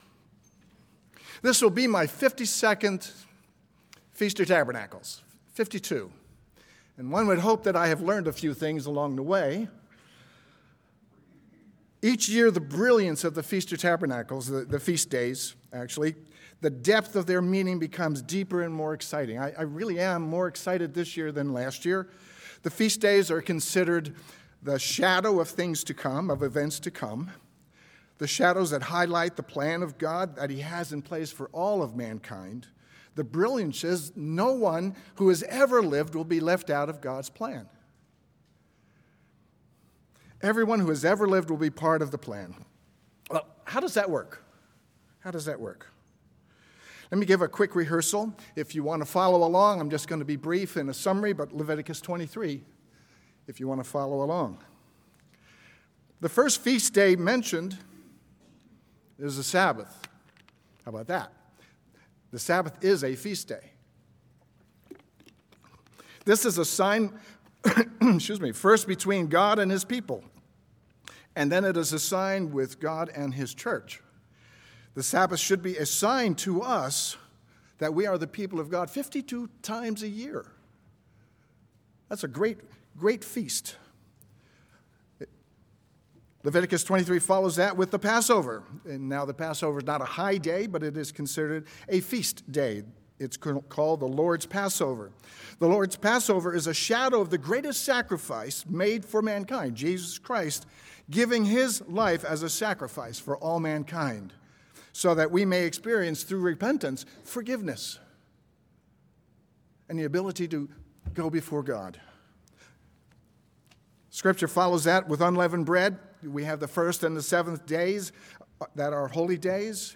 This sermon was given at the Bend-Redmond, Oregon 2021 Feast site.